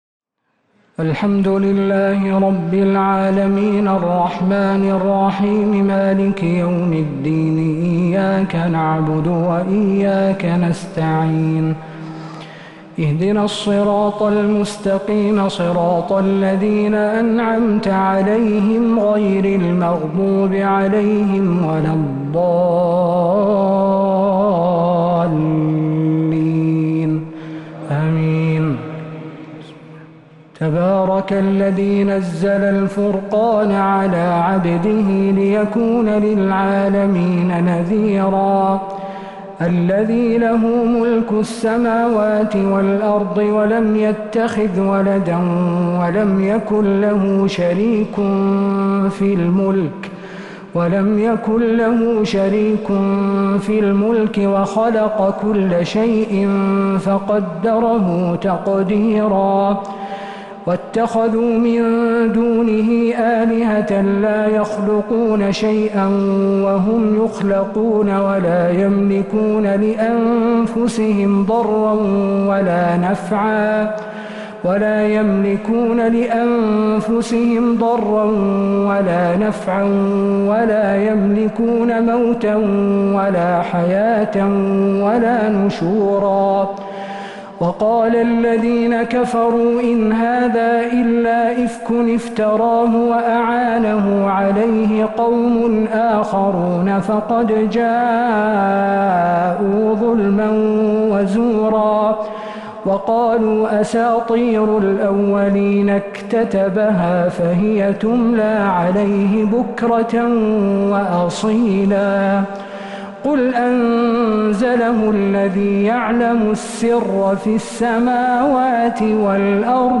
تهجد ليلة 22 رمضان 1447هـ سورة الفرقان كاملة و الشعراء (1-22) | Tahajjud 22nd night Ramadan1447H Surah Al-Furqan and Al-Shua’ara > تراويح الحرم النبوي عام 1447 🕌 > التراويح - تلاوات الحرمين